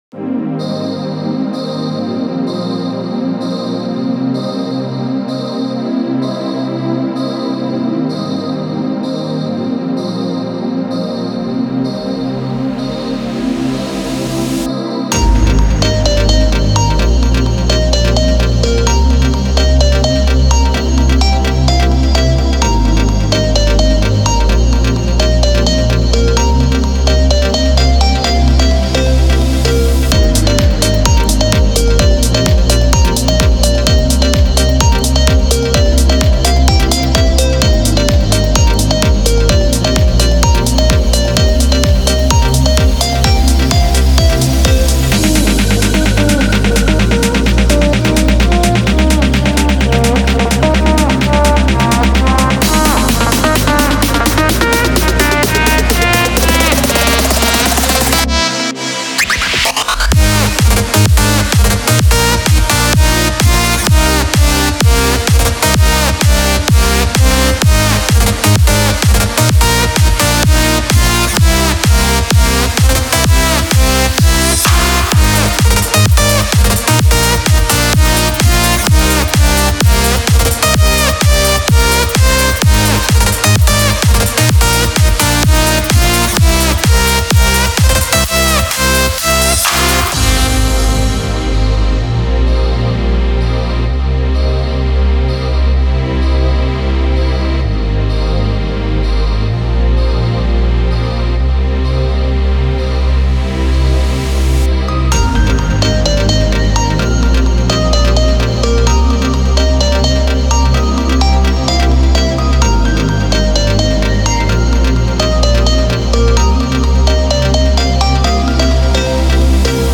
EDM(ダンスミュージック)というジャンルの曲なのですが、YouTubeやこのブログでも紹介しています。